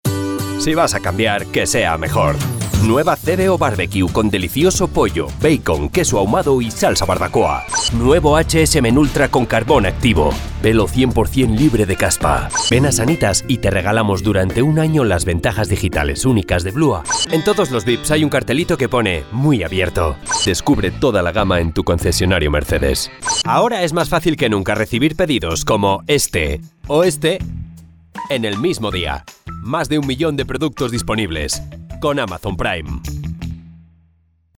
Trustworthy or irreverent personality, fresh & cool.
Sprechprobe: Werbung (Muttersprache):
★MY RIG★ -Mic. RODE NT1A -Preamp. Focusrite Scarlett Solo 2nd gen. -SONY MDR-7506 Headphones -DAW. Adobe Audition CC 2019 -Intel i5 / 16 RAM Gb